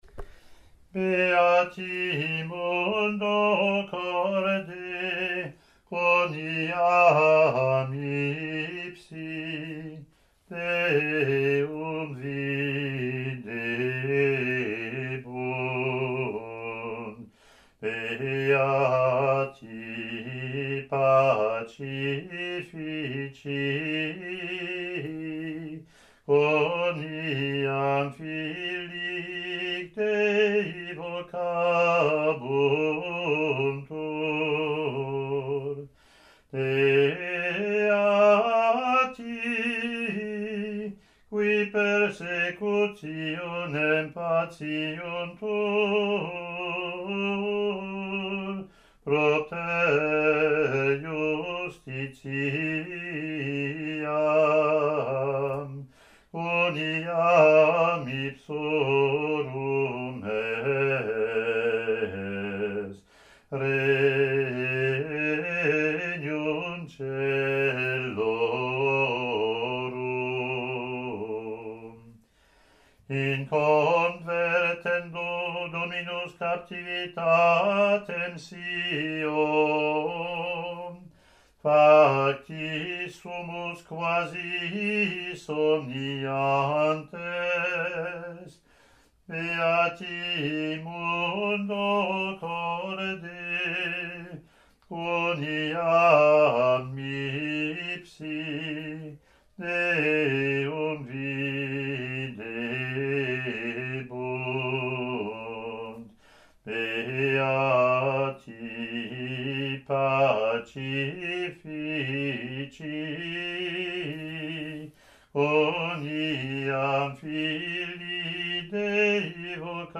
Latin antiphon + verse)